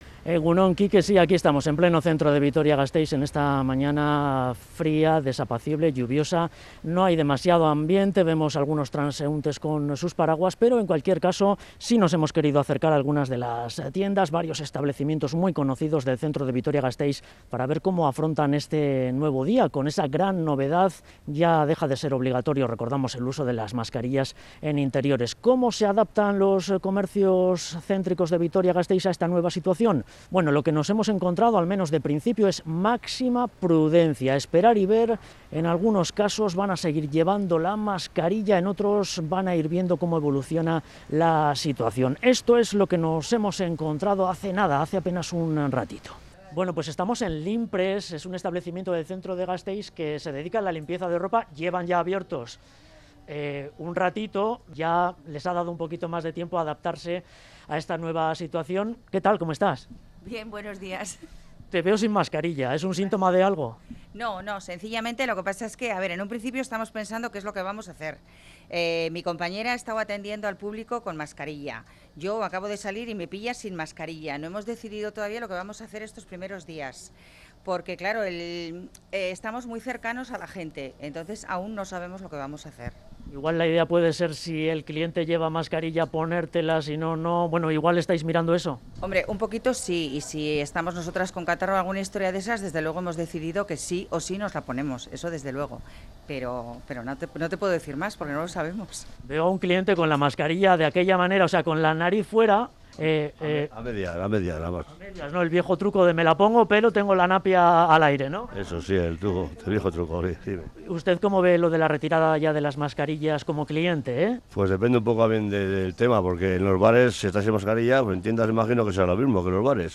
En Onda Vasca, varias dependientas de comercios pequeños de Gasteiz nos han contado que mucha de su clientela es mayor, y prefieren mantenerla para protegerles